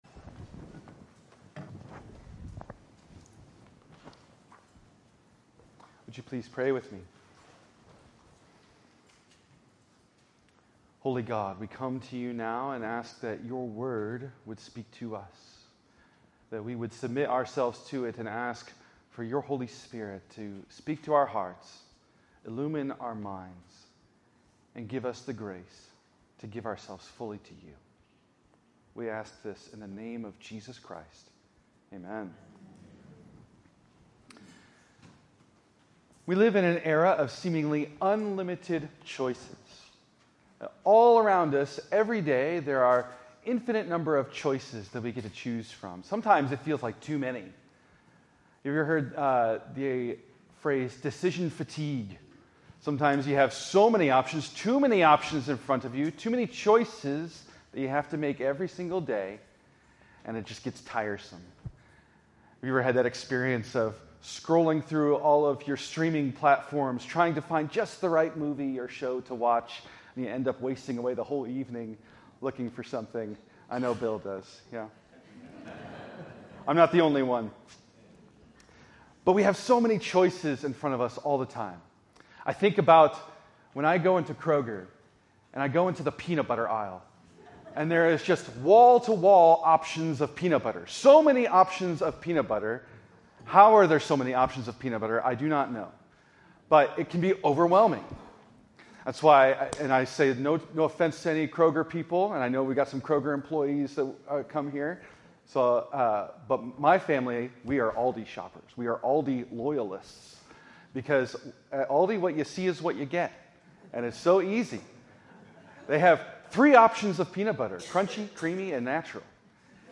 Save Audio In this sermon